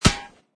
metalgrass3.mp3